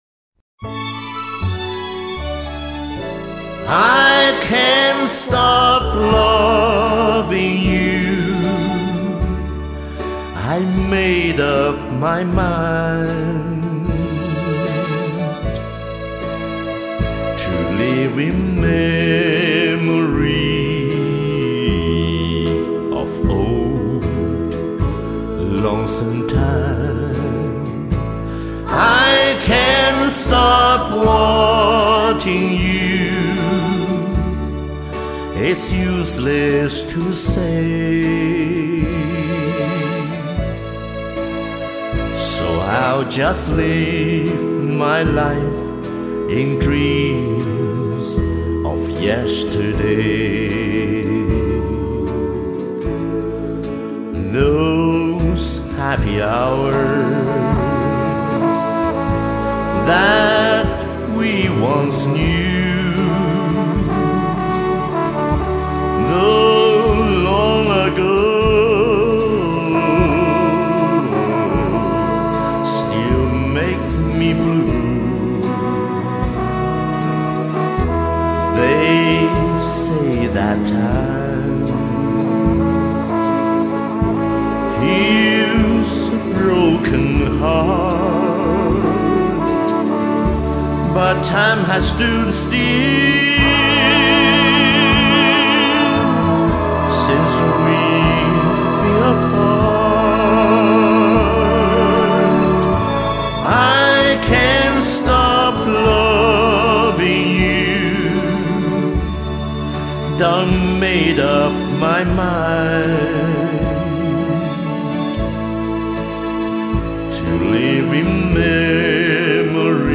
味道太浓了，低音的控制厉害，赞